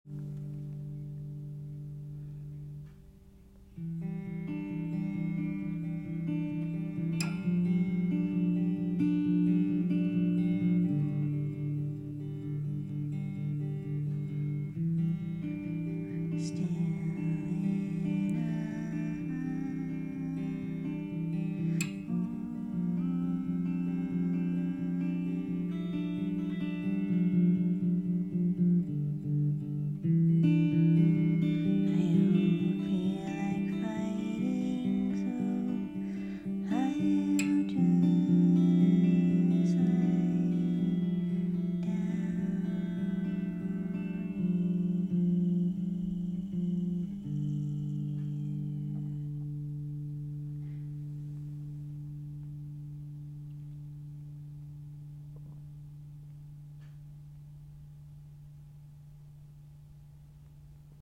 iphone recording, written 9/24 still in a war. i don’t feel like fighting so i’ll just lie down here